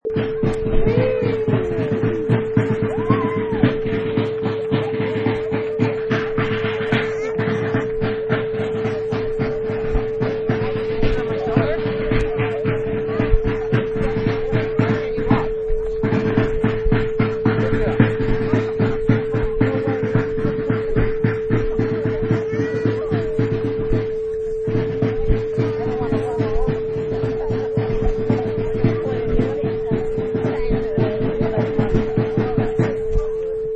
Marching Snare
Product Info: 48k 24bit Stereo
Category: Ambiences/Backgrounds / Parades
Relevant for: band, horns.
Try preview above (pink tone added for copyright).
Marching_Snare.mp3